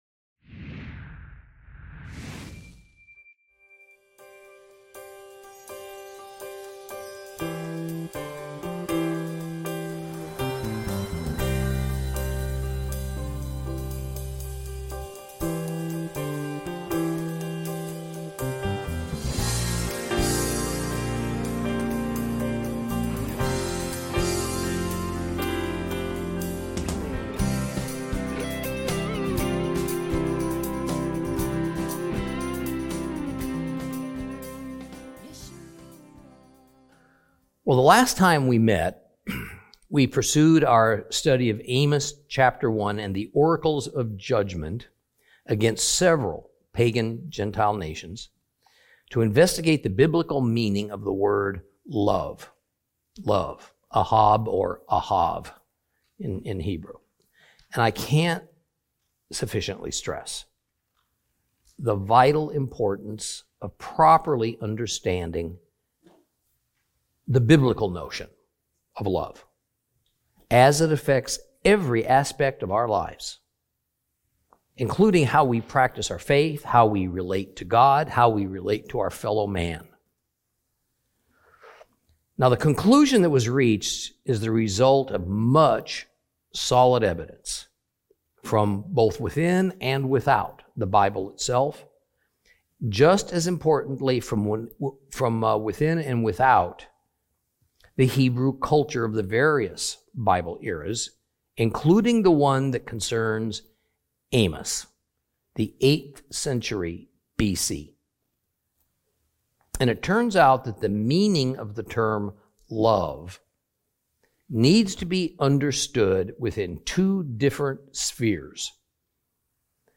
Teaching from the book of Amos, Lesson 3 Chapters 1 and 2.